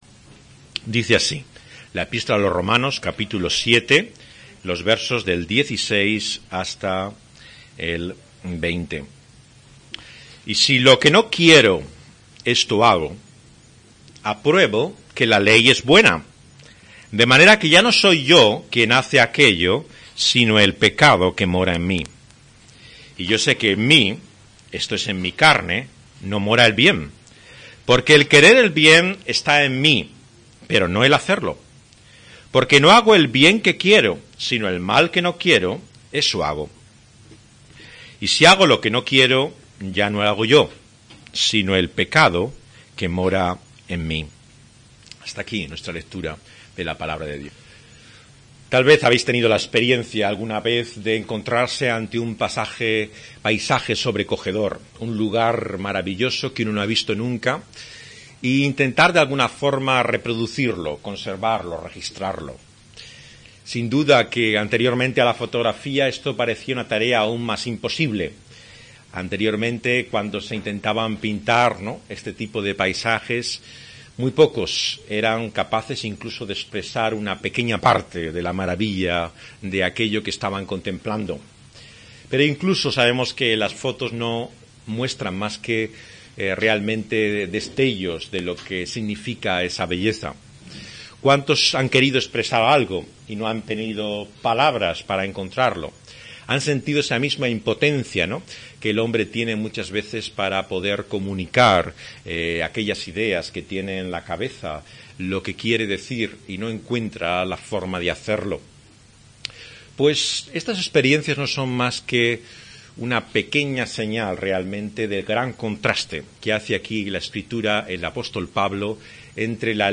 grabado en Madrid